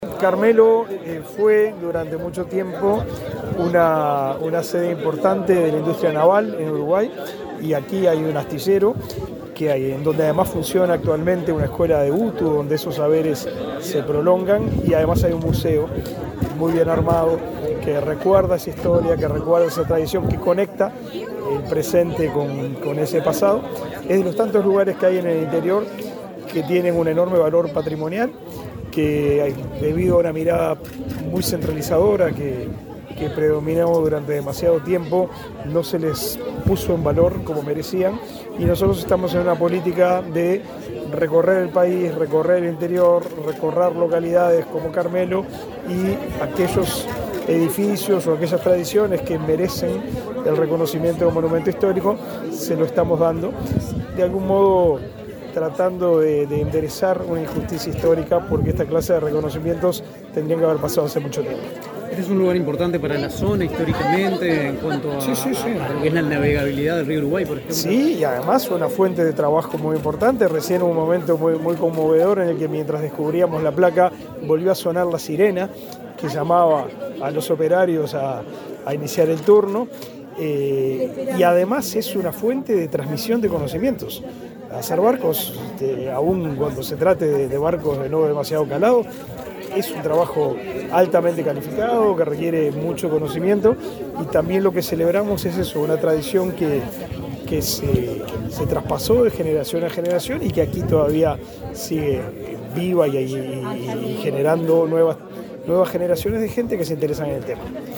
Declaraciones del ministro de Educación y Cultura, Pablo da Silveira
El Ministerio de Educación y Cultura realizó, este miércoles 11 en Colonia, un acto para declarar Monumento Histórico Nacional el patrimonio industrial formado por el conjunto de edificios que fuera conocido como astillero Carmelo. En ese marco, el titular de la cartera, Pablo da Silveira, dialogó con Comunicación Presidencial luego de la ceremonia.